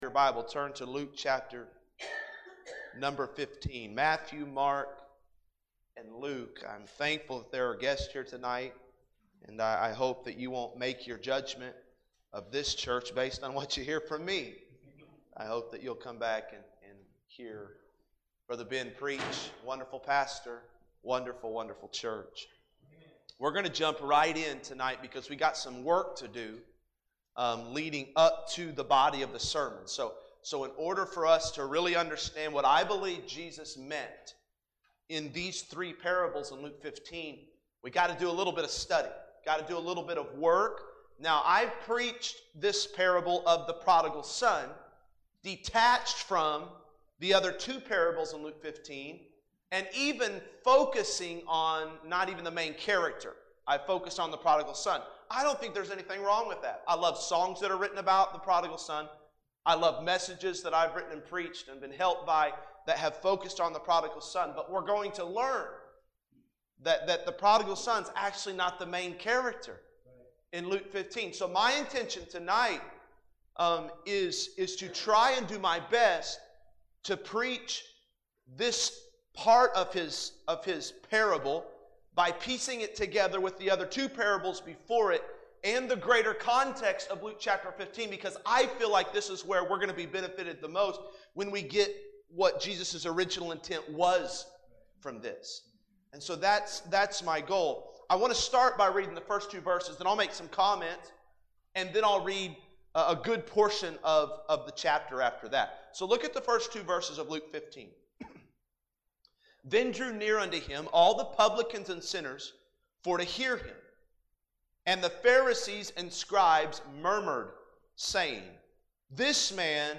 Luke 15:1-24 – Fall Revival Tuesday PM
Fall Revival 2020